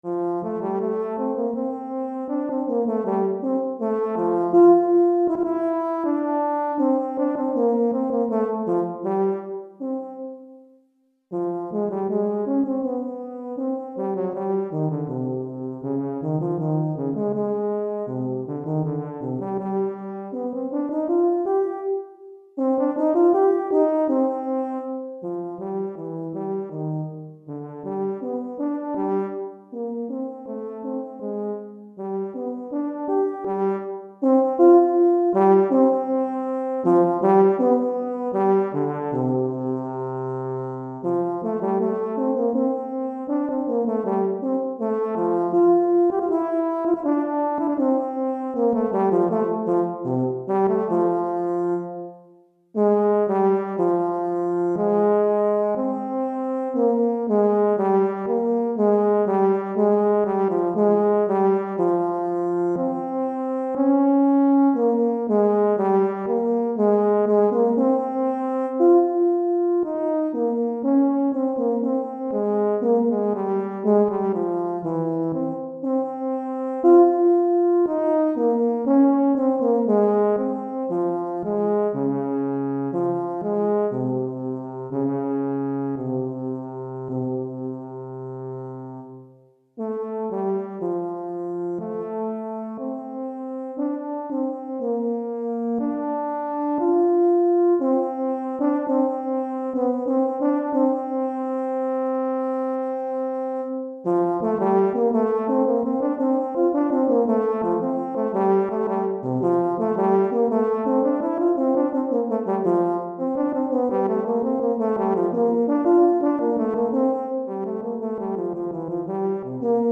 Pour saxhorn / euphonium solo DEGRE CYCLE 2